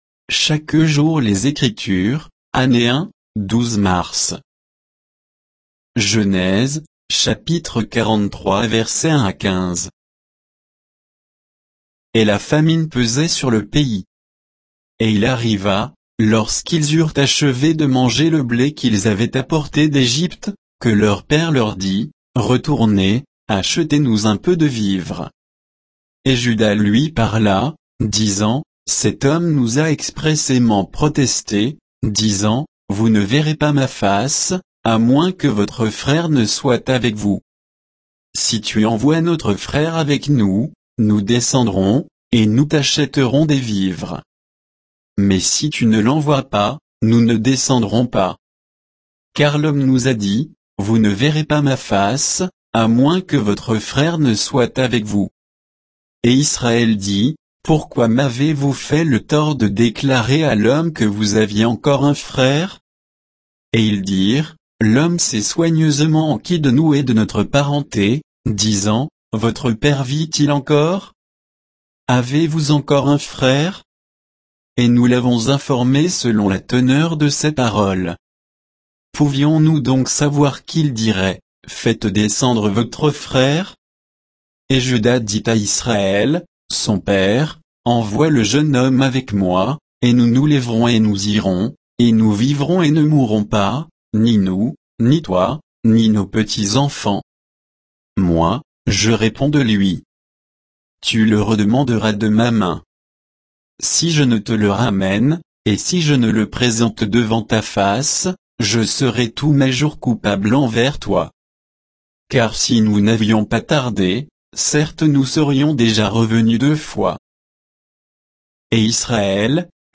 Méditation quoditienne de Chaque jour les Écritures sur Genèse 43